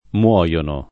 morire v.; muoio [mU0Lo], muori [mU0ri], muore [mU0re], moriamo [morL#mo], morite [mor&te], muoiono [